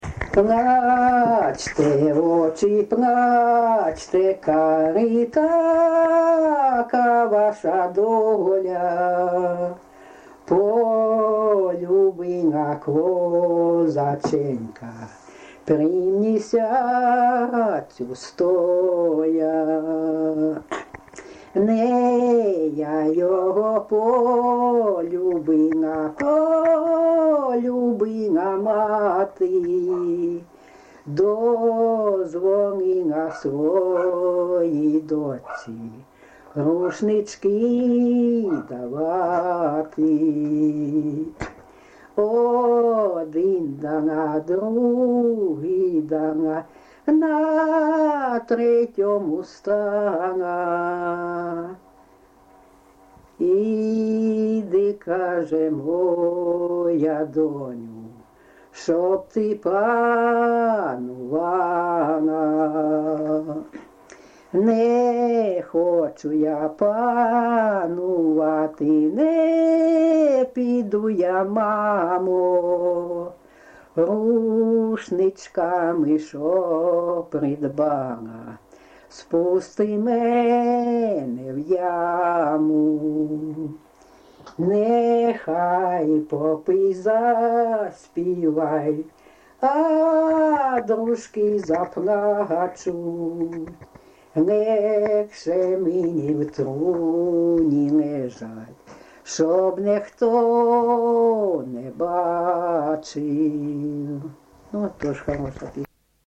ЖанрПісні з особистого та родинного життя
Місце записус. Курахівка, Покровський район, Донецька обл., Україна, Слобожанщина